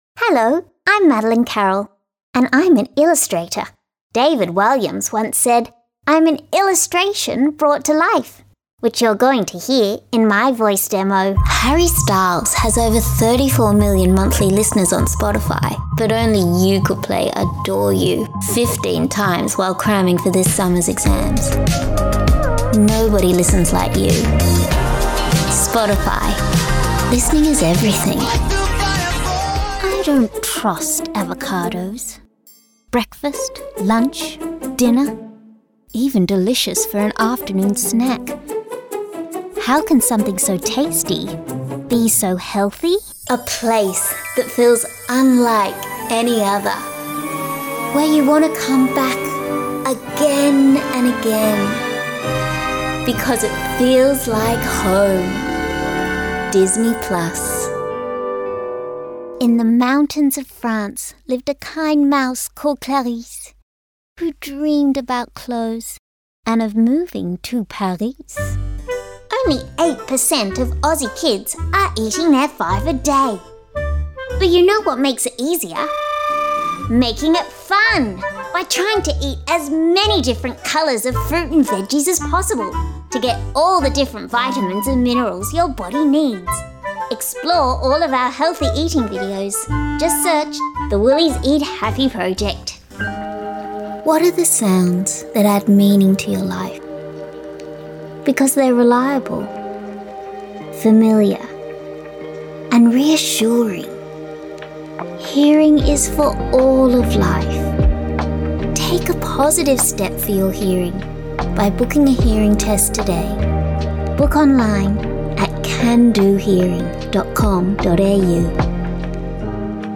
The Voice Realm features Ford voiceover commercials voiced by talent trusted by leading global brands.